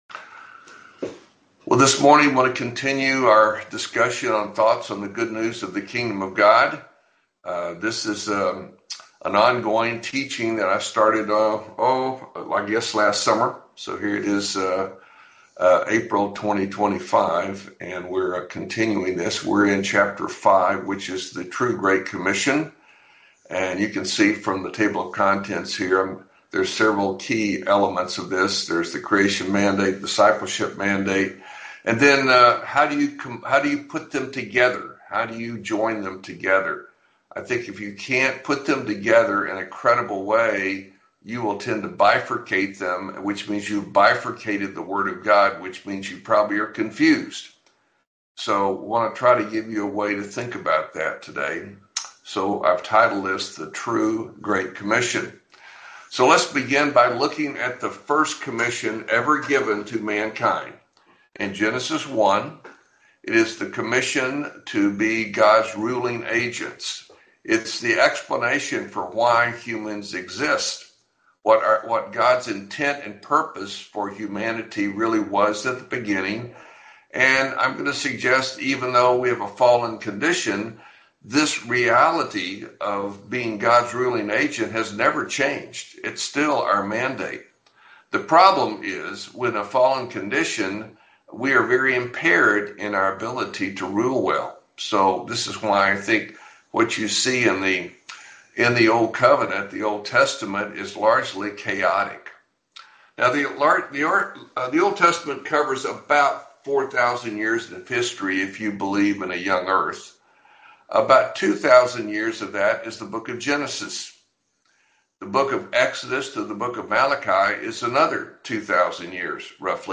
1 You Can Lead and Still Have a Life: A Real Talk